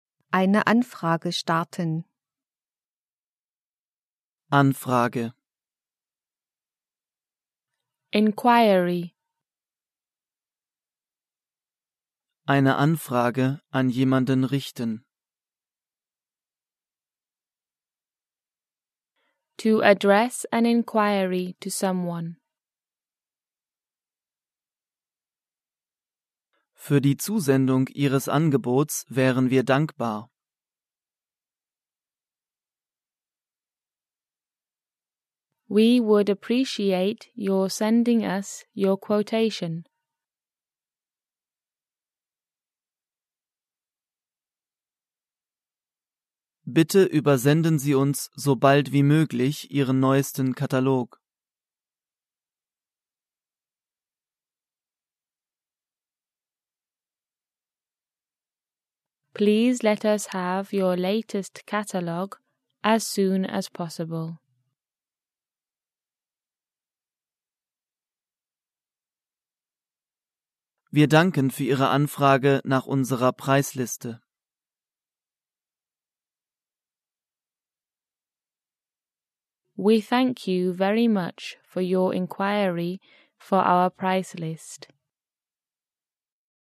Alle Wörter und Wendungen sind von Muttersprachlern gesprochen. Zuerst wird das deutsche Wort bzw. die deutsche Wendung genannt, dann folgt eine Sprechpause, in der der Lernende die korrekte Übersetzung nennen oder aufschreiben kann.
Dann folgt eine weitere Nachsprechpause. Alle deutschen und alle fremdsprachlichen Wörter und Wendungen sind mit einer jeweils anderen Stimme gesprochen.
Flotte Musik lockert das Lernen auf und erleichtert das Arbeiten.